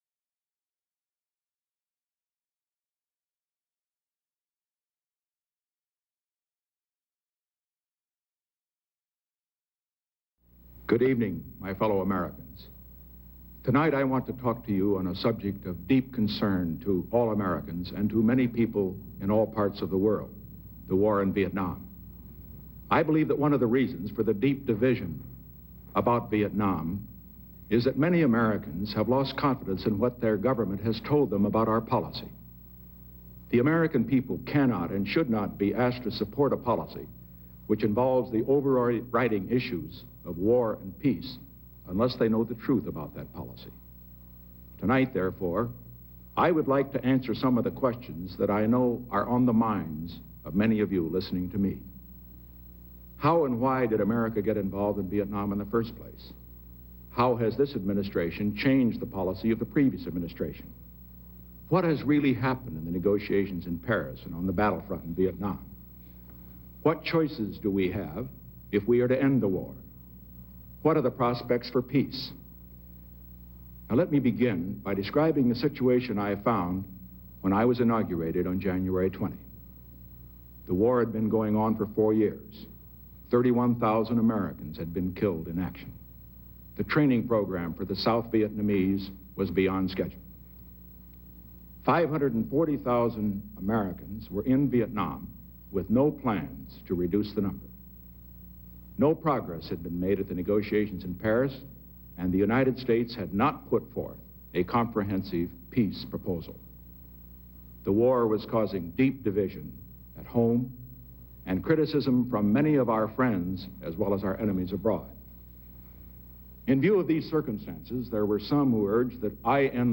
The Great Silent Majority Speech on MP3